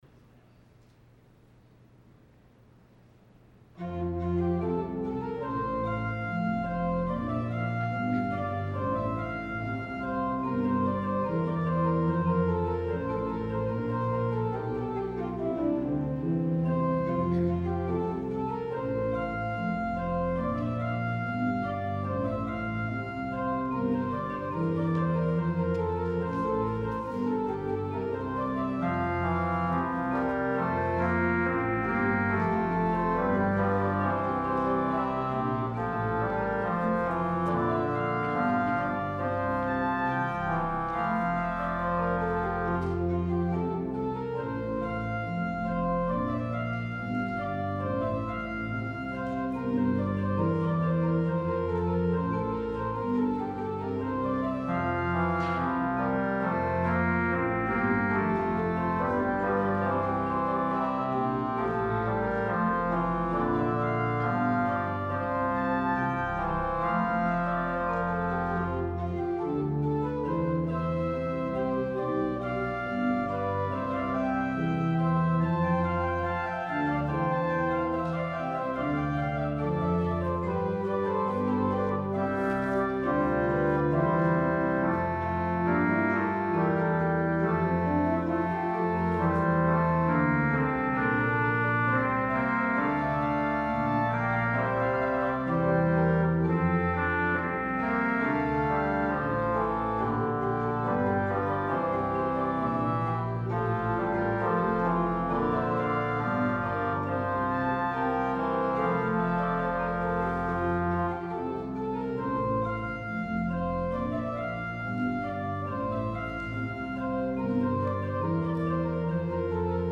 Sunday Worship 1-10-21 (The Baptism of Our Lord)